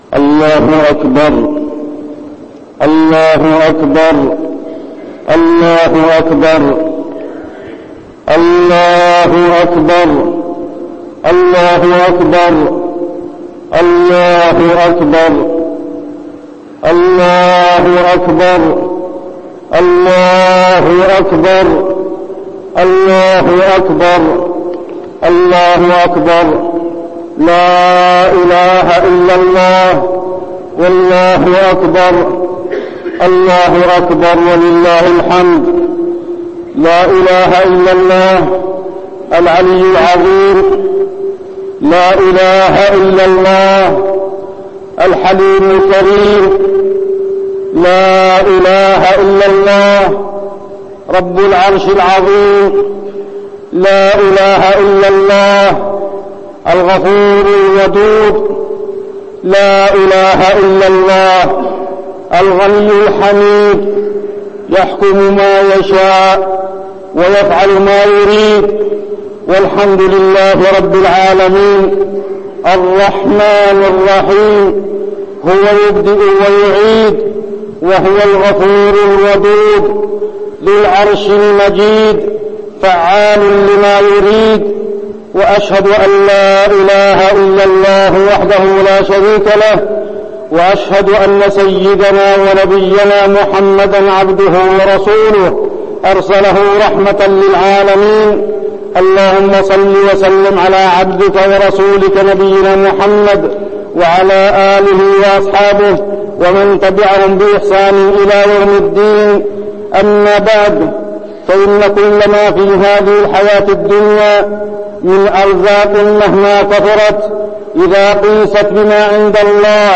خطبة الاستسقاء - المدينة- الشيخ عبدالله الزاحم
تاريخ النشر ٨ ربيع الثاني ١٤١٠ هـ المكان: المسجد النبوي الشيخ: عبدالله بن محمد الزاحم عبدالله بن محمد الزاحم خطبة الاستسقاء - المدينة- الشيخ عبدالله الزاحم